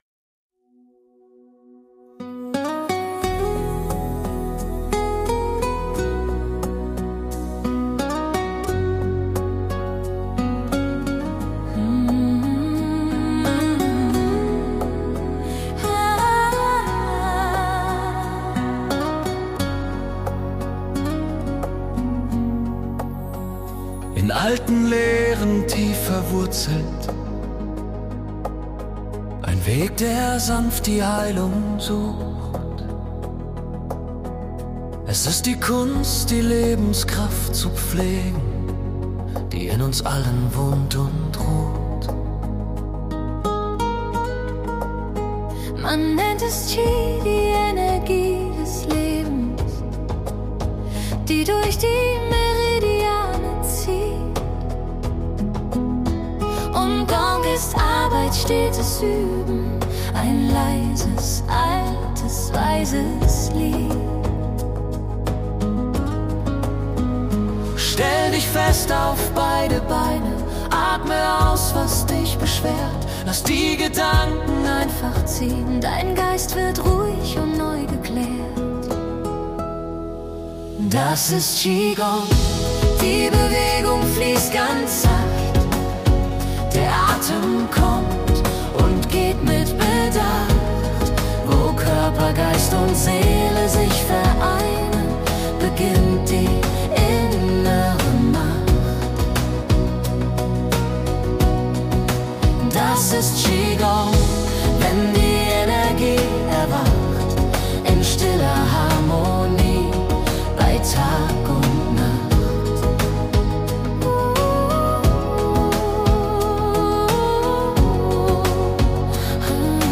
Komposition mit Suno AI